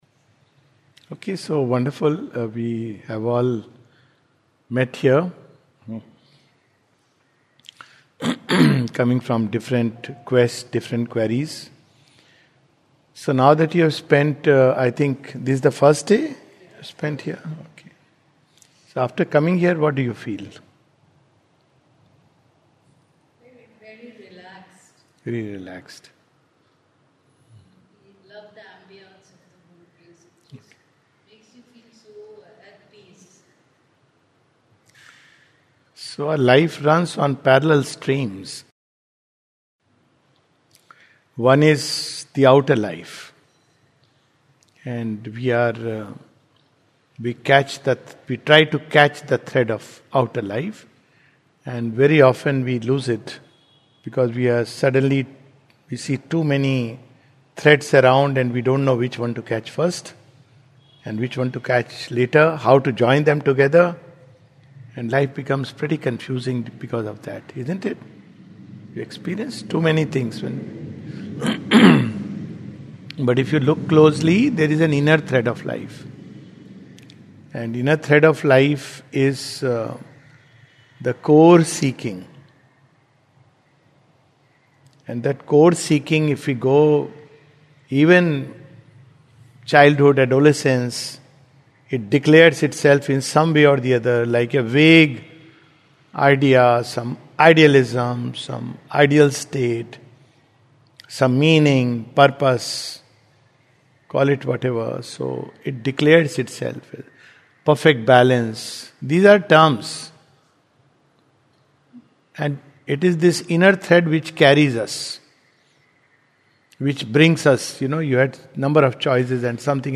This is a session at Matriniketan, Sri Aurobindo Society which takes up the seeking in life, its meaning purpose, the nature of God.